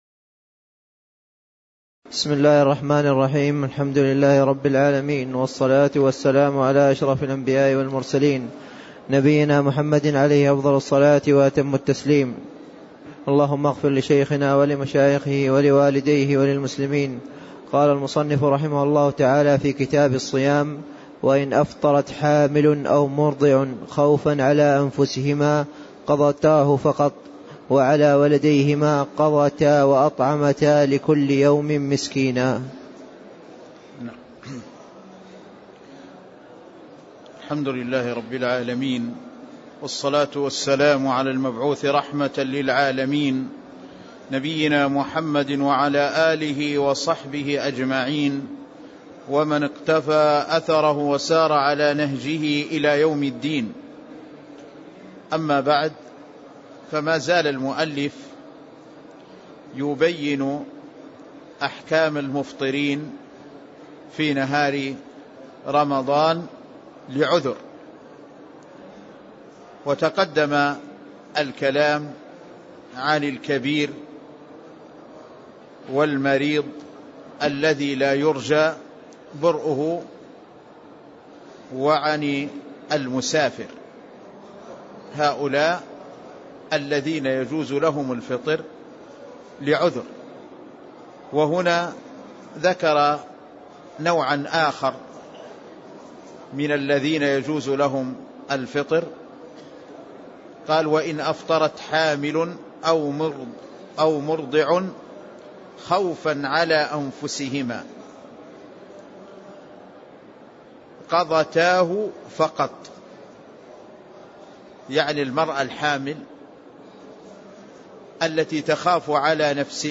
تاريخ النشر ٥ جمادى الأولى ١٤٣٦ هـ المكان: المسجد النبوي الشيخ